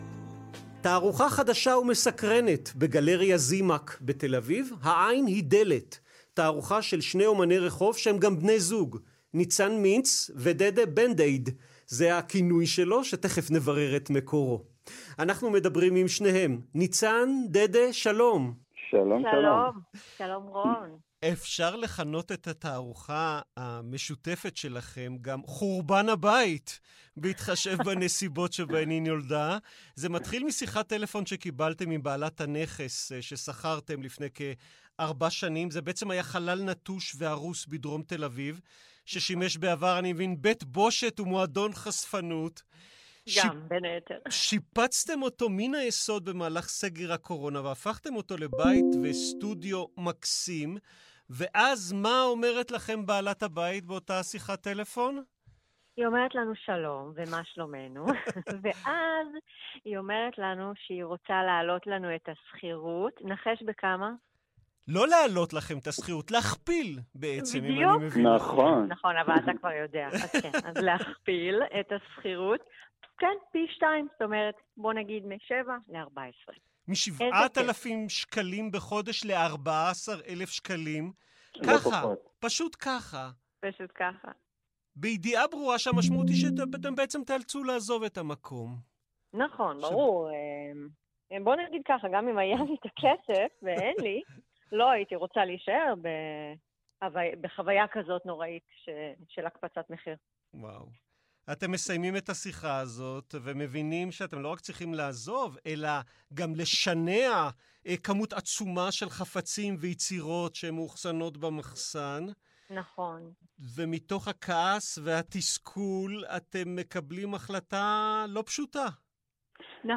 2ראיון-לכאן-ב.m4a